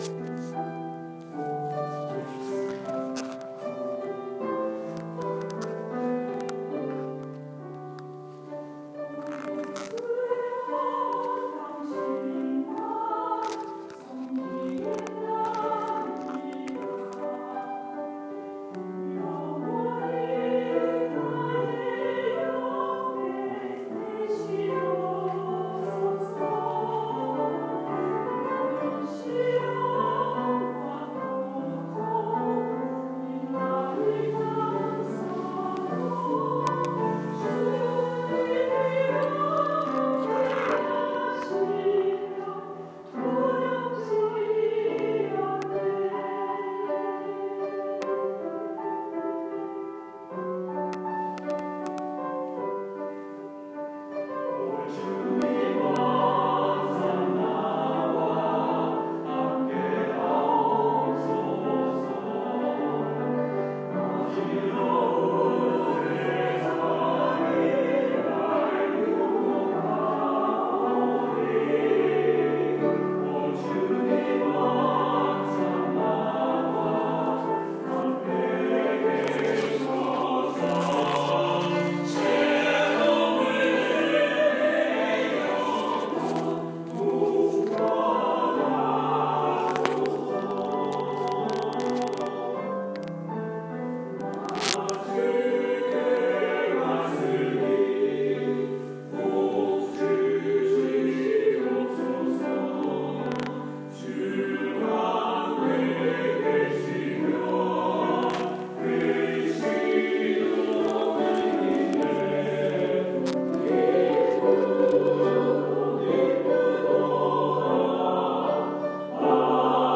10월 15일 찬양대 특송(가을 부흥회 둘째날)